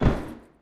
scpcb-godot/SFX/Character/MTF/Step3.ogg at d1278b1e4f0e2b319130f81458b470fe56e70c55
Step3.ogg